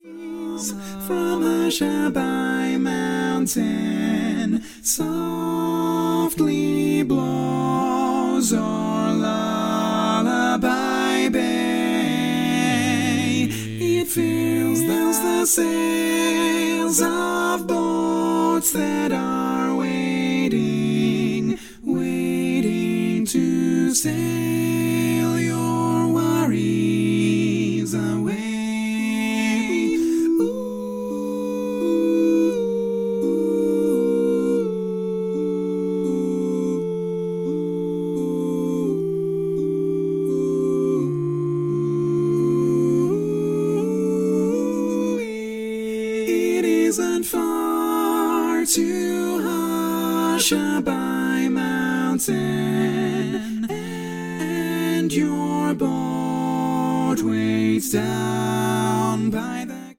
Full mix only